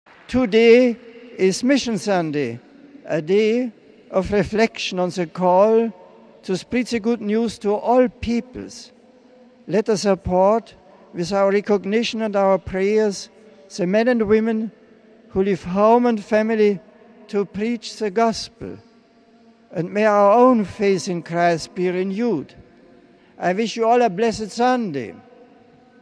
He made that observation at midday, when praying the traditional Sunday 'Angelus' from the window of his study with some 30,000 people gathered in St. Peter's Square.
He also greeted the English-speaking faithful on Mission Sunday.